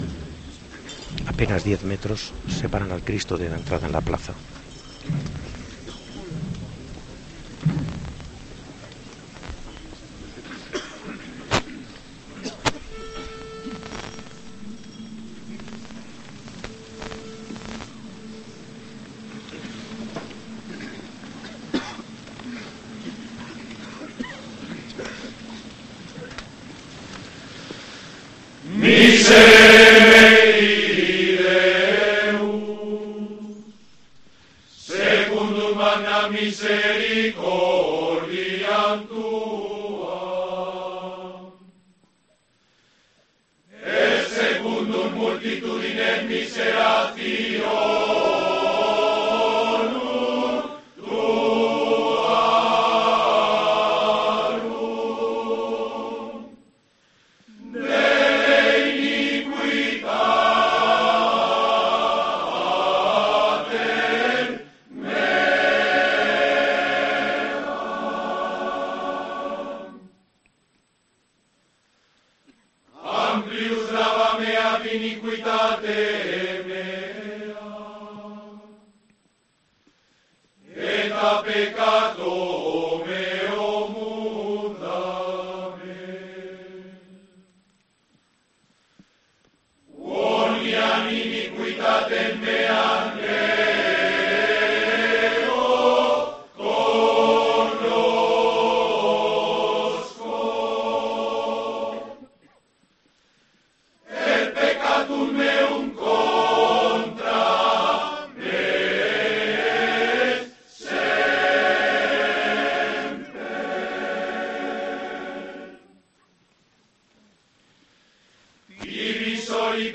El Miserere de la Hermandad de Jesús Yacente ha desafiado la lluvia en Zamora la madrugada de este viernes y el tradicional canto gregoriano se ha escuchado ante el atento oído de cientos de personas que, paraguas en mano, se han congregado en la plaza de Viriato de la capital zamorana.
Más de un centenar de integrantes del coro han tapado el sonido de las gotas de agua al caer en el suelo empedrado de la plaza de Viriato con el Salmo 50 cantado en latín que comienza "Miserere meus dei".
El silencio al paso del desfile únicamente lo han roto, además del tintineo de la lluvia, el golpeteo de los hachones en el suelo y las campanillas del viático que anuncian la presencia de Jesucristo.
También se ha podido escuchar el ruido de las tres cruces de madera de tamaño y peso similar a la del Crucificado que son arrastradas, cada una de ellas por un cofrade como signo de penitencia.